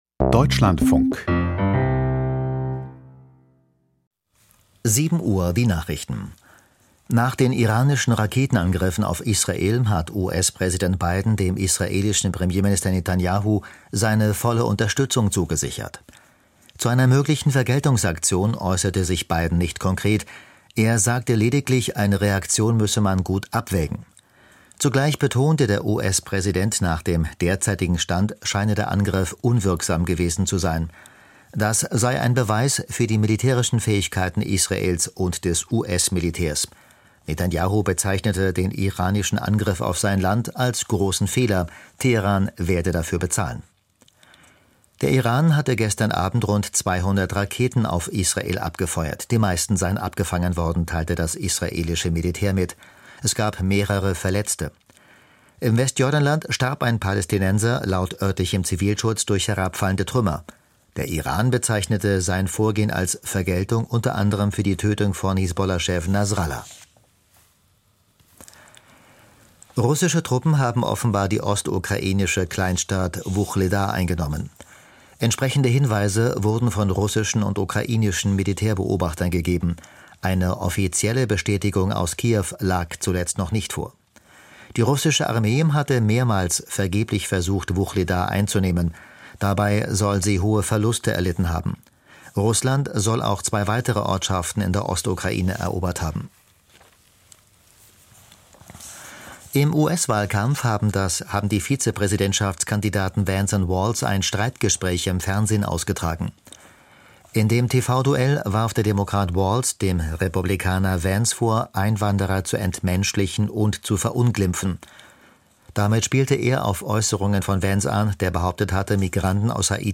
Die Deutschlandfunk-Nachrichten vom 02.10.2024, 07:00 Uhr